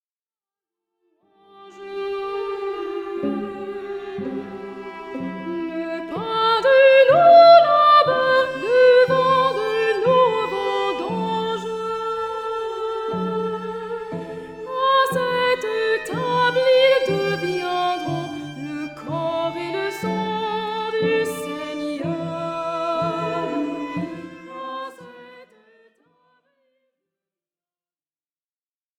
Format :MP3 256Kbps Stéréo